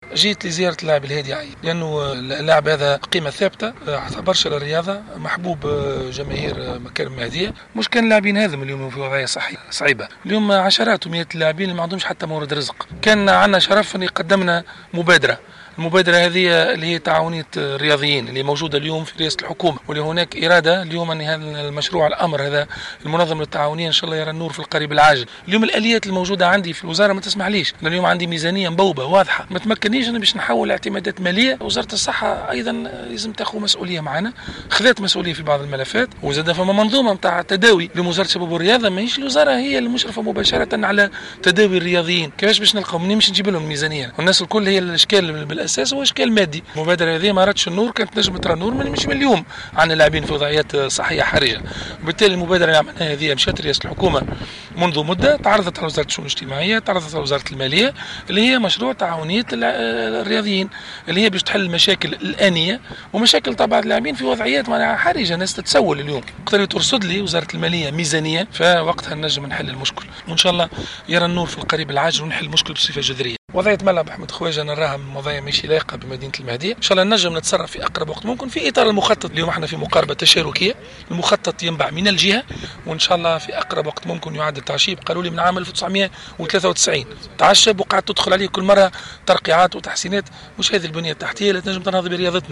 ماهر بن ضياء : وزير الشباب و الرياضة